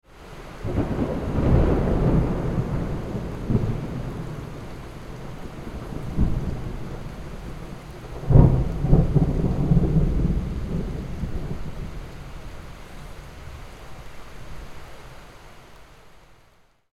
Nature Sounds Thunder And Rain
Description: Nature sounds thunder and rain.
Genres: Sound Effects
Nature-sounds-thunder-and-rain.mp3